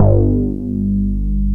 RNDMOOG1.wav